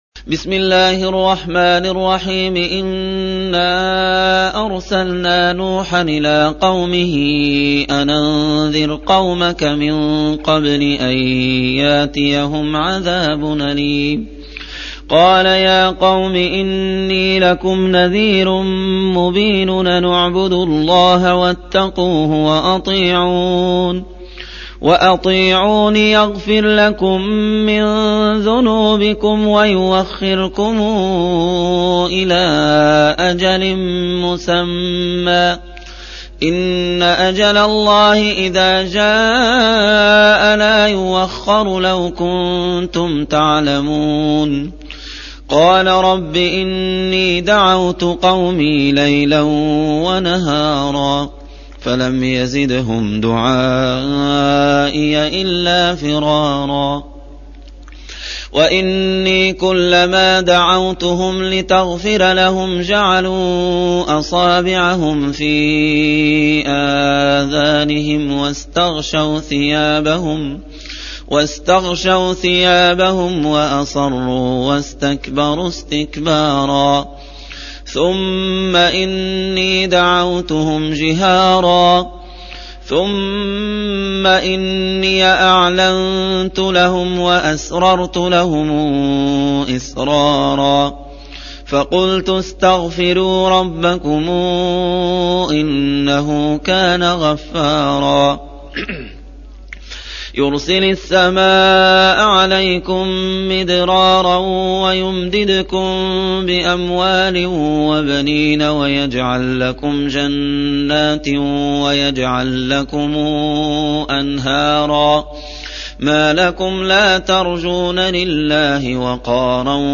71. Surah N�h سورة نوح Audio Quran Tarteel Recitation
Surah Sequence تتابع السورة Download Surah حمّل السورة Reciting Murattalah Audio for 71.